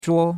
zhuo1.mp3